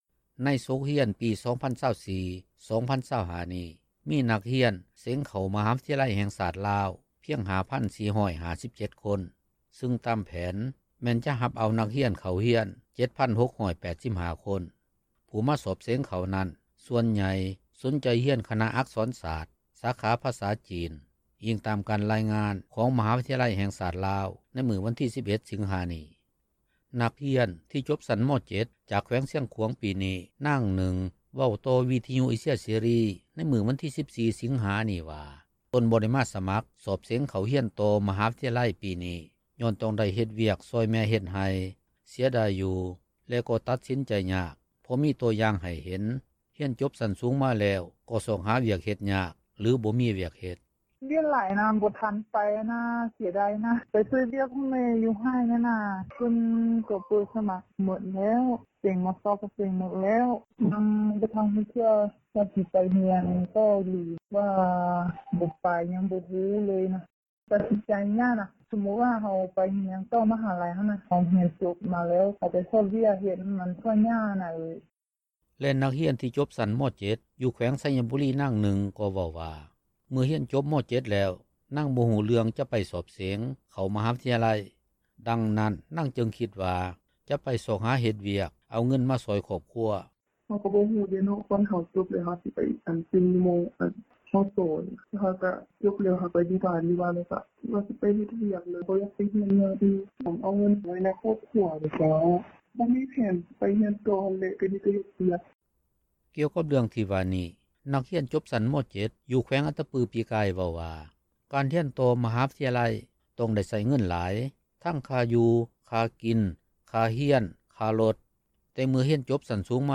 ນັກຮຽນ ທີ່ຈົບຊັ້ນ ມ.7 ຈາກແຂວງຊຽງຂວາງປີນີ້ ນາງນຶ່ງເວົ້າຕໍ່ວິທຍຸເອເຊັຍເສຣີ ໃນມື້ວັນທີ 14 ສິງຫານີ້ວ່າ ຕົນບໍ່ໄດ້ມາສະໝັກສອບເສງເຂົ້າຮຽນຕໍ່ ມະ ຫາວິທະຍາໄລປີນີ້ ຍ້ອນຕ້ອງໄດ້ເຮັດວຽກຊ່ອຍແມ່ເຮັດໄຮ່ ເສຽດາຍຢູ່ ແລະກໍຕັດສິນໃຈຍາກ ເພາະມີໂຕຢ່າງໃຫ້ເຫັນ ຮຽນຈົບຊັ້ນສູງມາແລ້ວ ກໍຊອກວຽກເຮັດຍາກ ຫຼືບໍ່ມີວຽກເຮັດ.